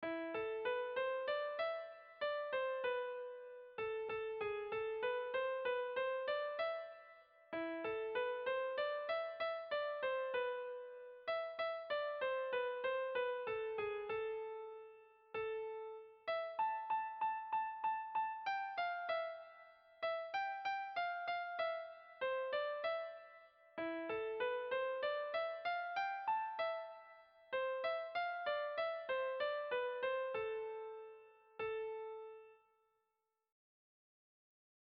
Sentimenduzkoa
Zortziko txikia (hg) / Lau puntuko txikia (ip)
A1A2BA3